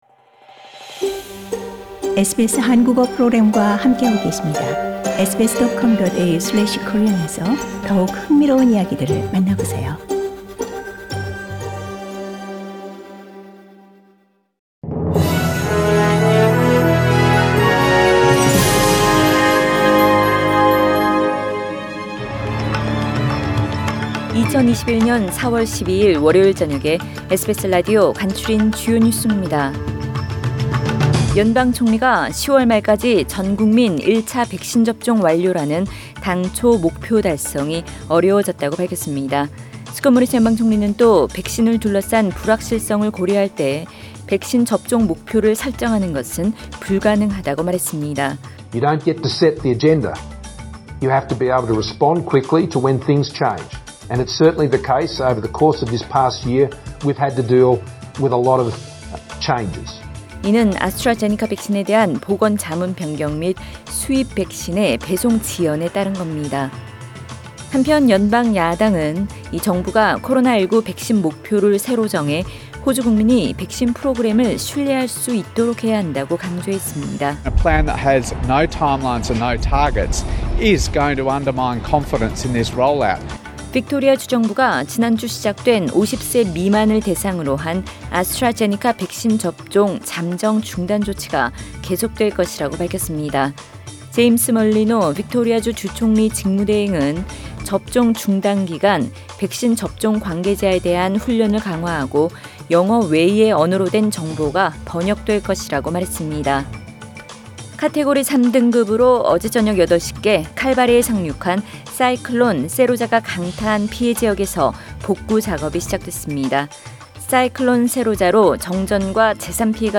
2021년 4월 12일 월요일 저녁의 SBS 뉴스 아우트라인입니다.